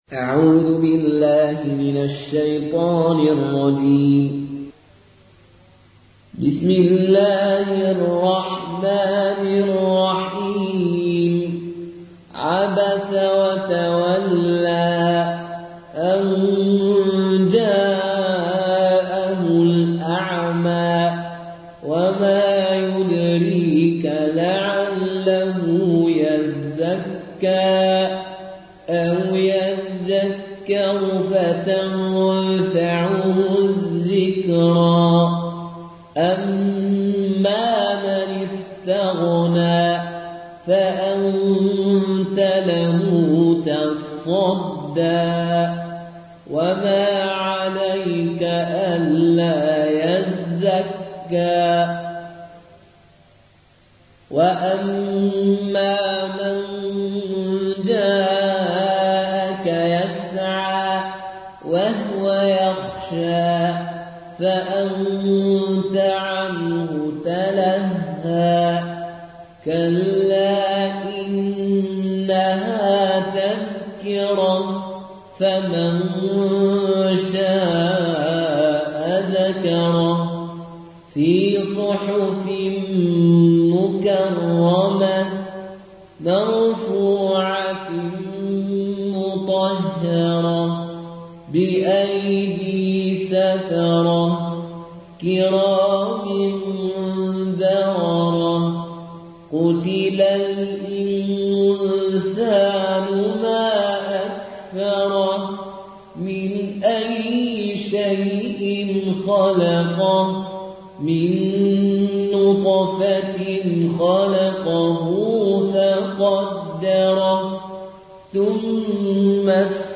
Qaloon an Nafi